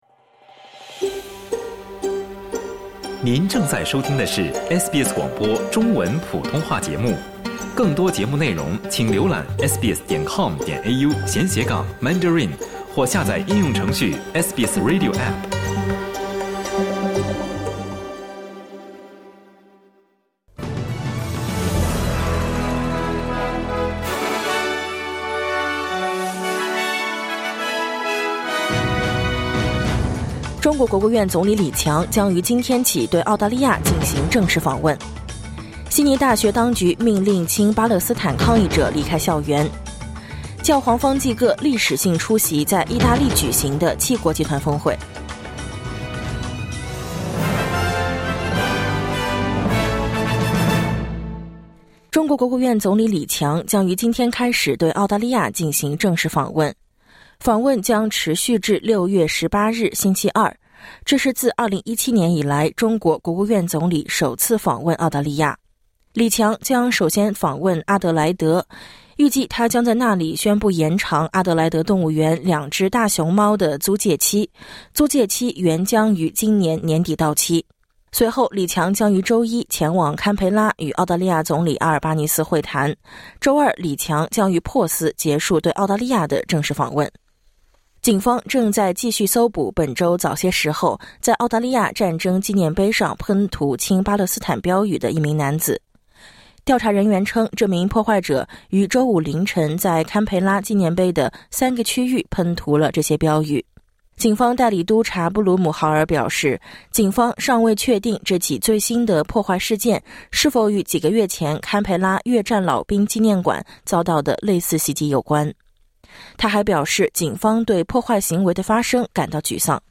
SBS早新闻（2024年6月15日）